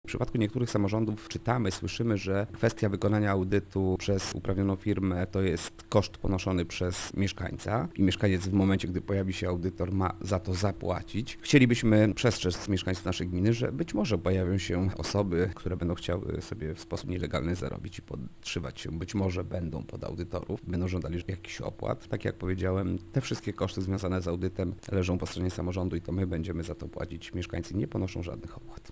Mariusz Osiak podkreśla, że audytorzy nie pobierają żadnych opłat: